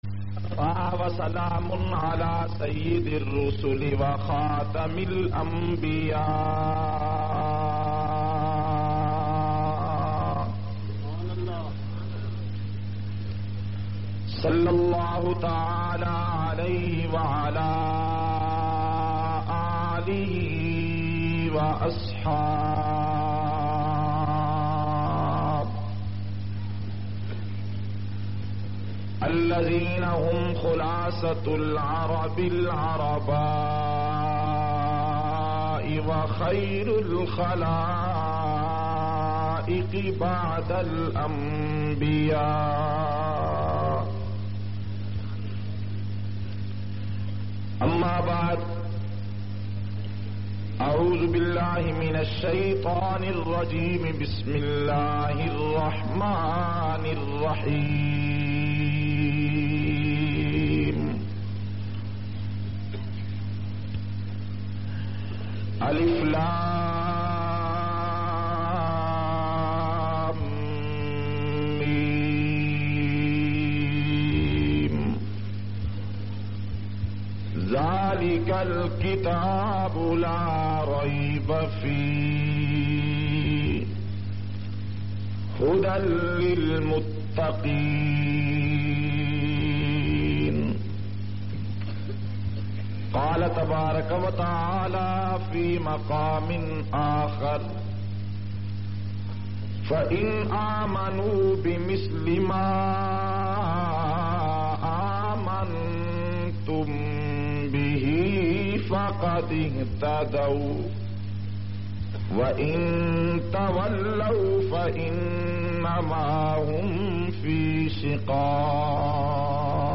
702- BBC Interview Ki Tafseelat.mp3